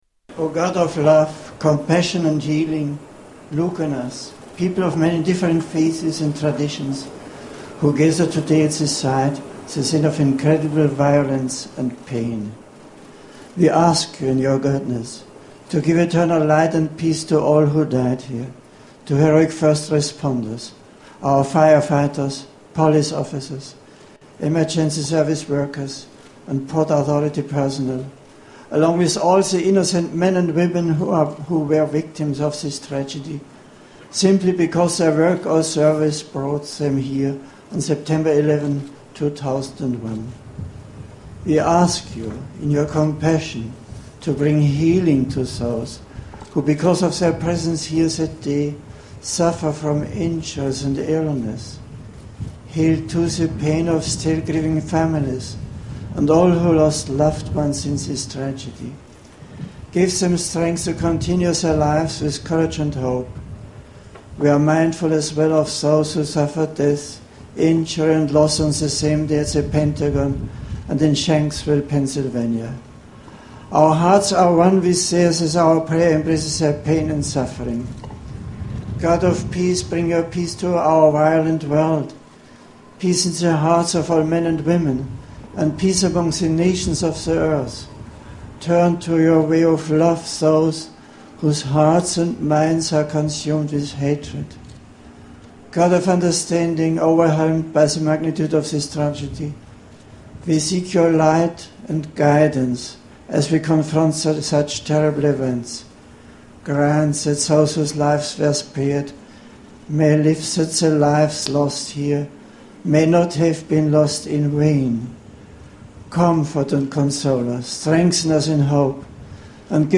Pope prays at Ground Zero